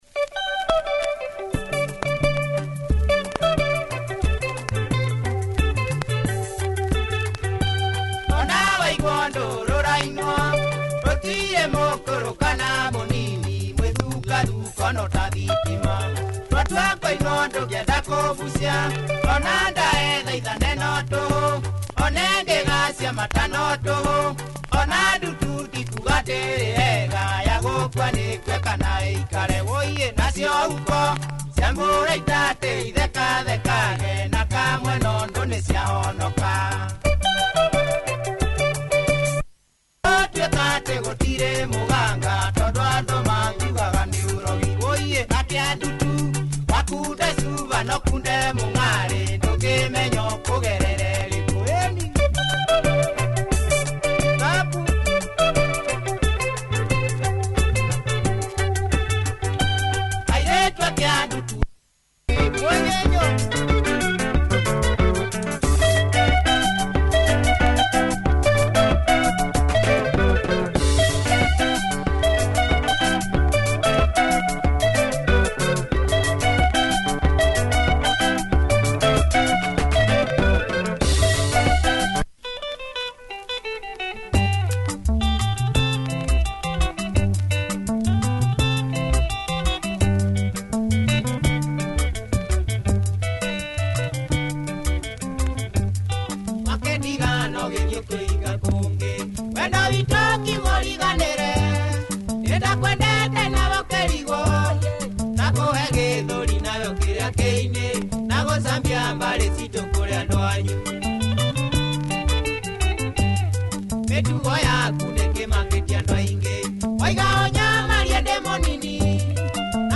Nice punchy Kikuyu benga, check audio! https